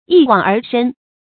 一往而深 注音： ㄧ ㄨㄤˇ ㄦˊ ㄕㄣ 讀音讀法： 意思解釋： 見「一往情深」。